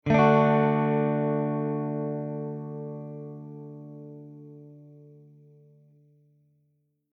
They are three tones or more played together at the same time.
D Chord
dchord.mp3